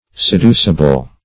Seducible \Se*du"ci*ble\, a. Capable of being seduced; corruptible.